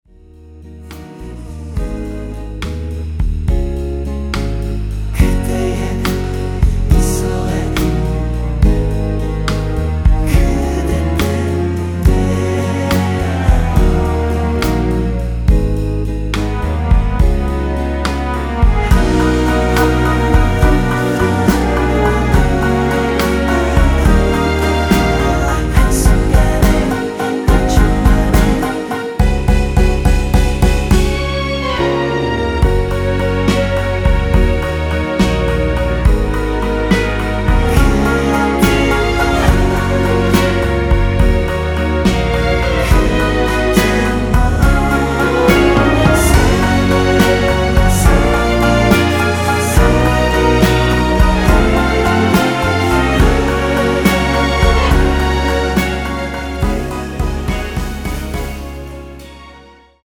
원키 코러스 포함된 MR입니다.
F#
앞부분30초, 뒷부분30초씩 편집해서 올려 드리고 있습니다.
중간에 음이 끈어지고 다시 나오는 이유는